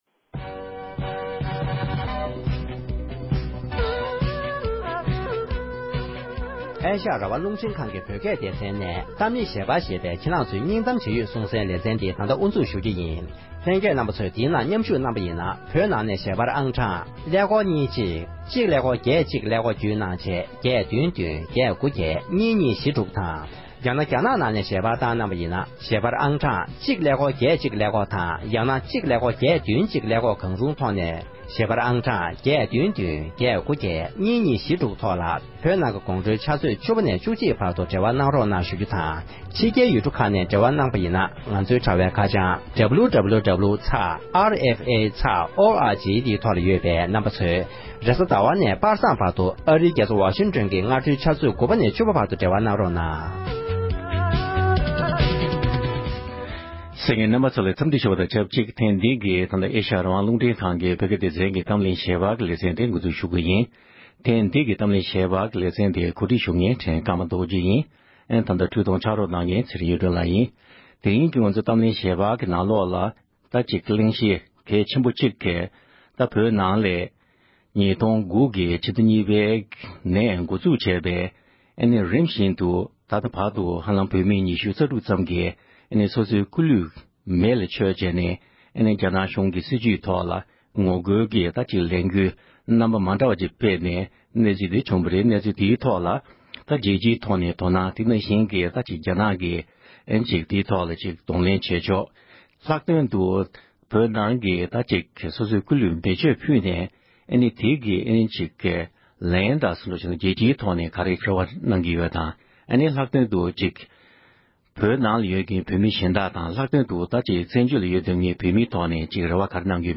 བོད་ནང་བོད་མི་རྣམས་ཀྱིས་རང་ལུས་མེ་བསྲེག་བཏང་སྟེ་ལས་འགུལ་སྤེལ་བའི་མཚོན་དོན་དང་རྒྱ་ནག་གི་ལན་འདེབས་ཐད་བགྲོ་གླེང༌།